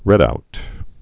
(rĕdout)